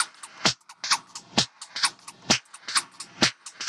Index of /musicradar/uk-garage-samples/130bpm Lines n Loops/Beats
GA_BeatErevrev130-06.wav